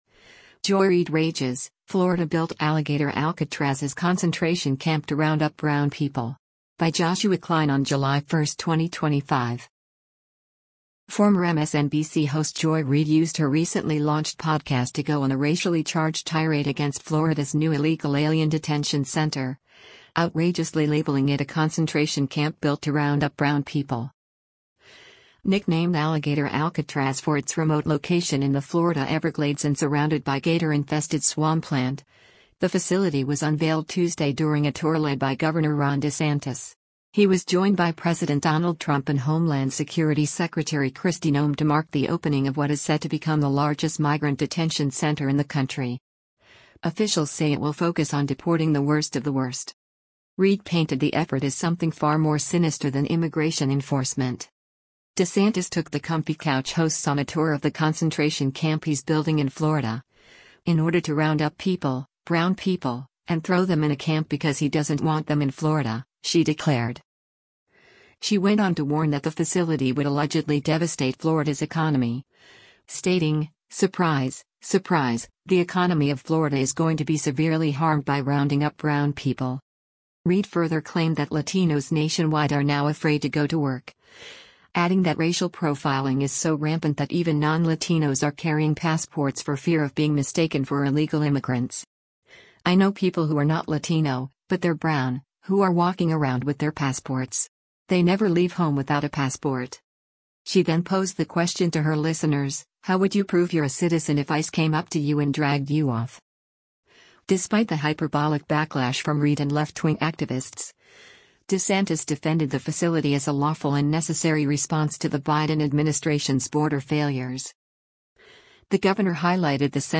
Former MSNBC host Joy Reid used her recently launched podcast to go on a racially charged tirade against Florida’s new illegal alien detention center, outrageously labeling it a “concentration camp” built to “round up brown people.”